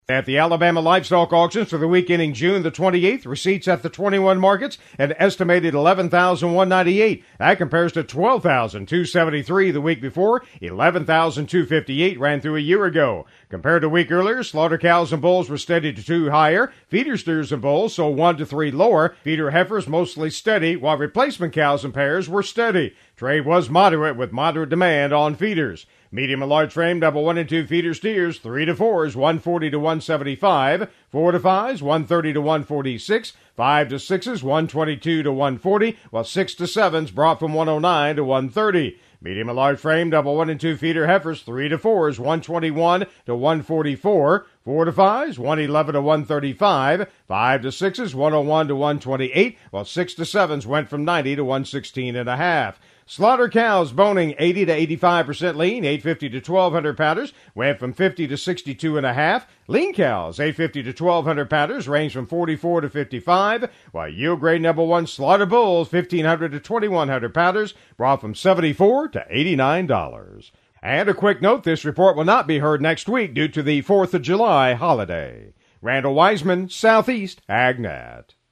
AL Livestock Market Report: